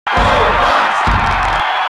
Fox's cheer in the US and PAL versions of Smash 64.
Fox_Cheer_International_SSB.ogg